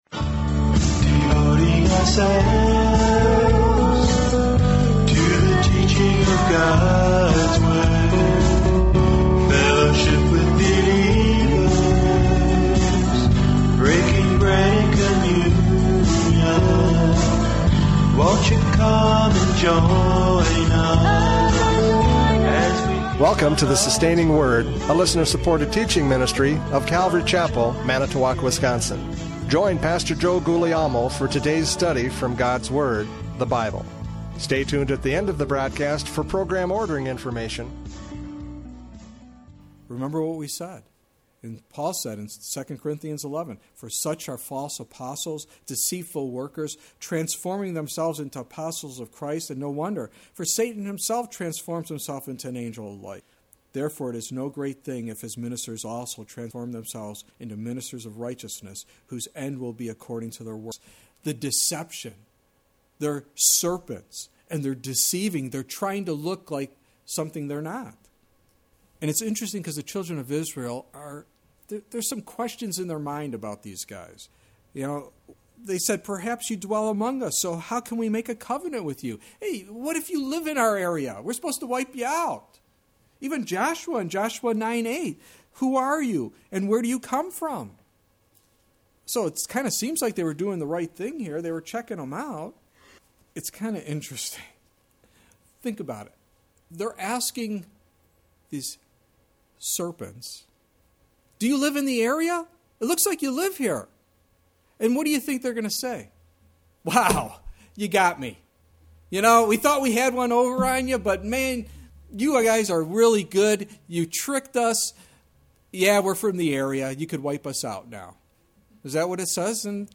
Joshua 9:1-13 Service Type: Radio Programs « Joshua 9:1-13 The Agony of Deceit!